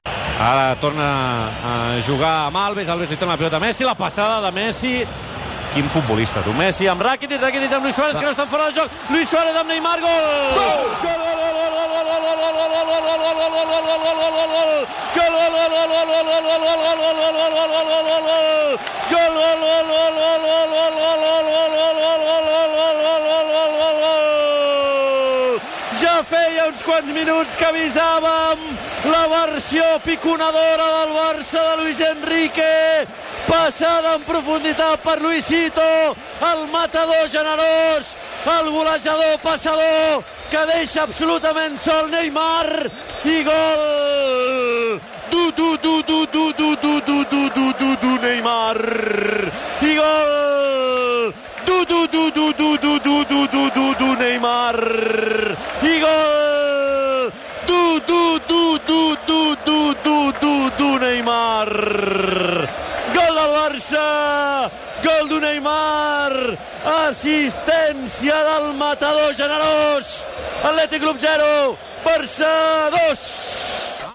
Transmissió del partit de la Copa del Rei de futbol masculí entre l'Athletic Club i el Futbol Club Barcelona.
Narració del gol de Neymar.
Esportiu